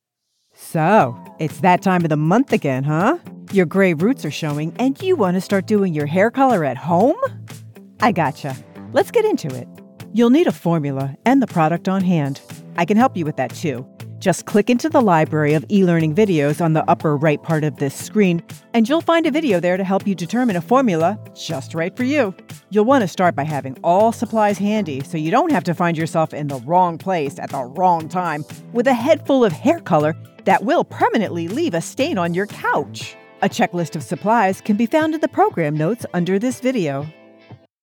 explainer, casual, knowledgeable, approachable